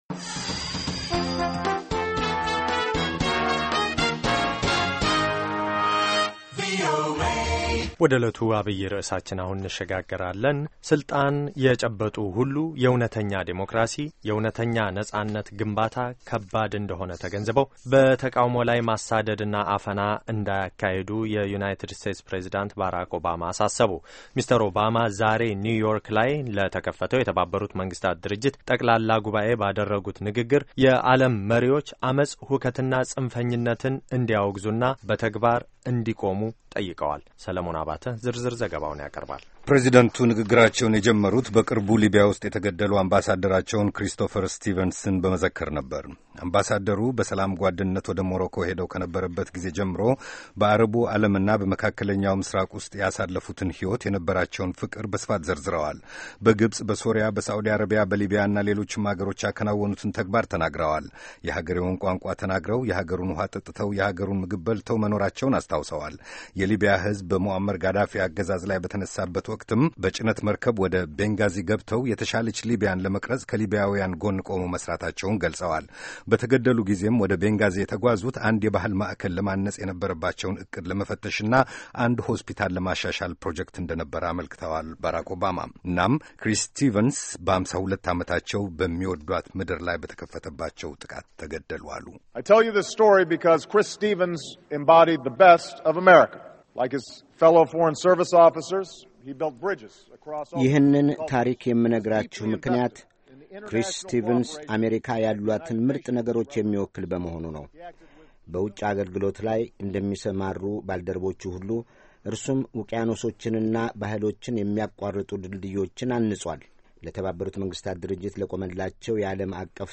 Remarks by President Barak Obama on the Opening of the 67th UNGA; 25 September 2012; New York. Amharic report.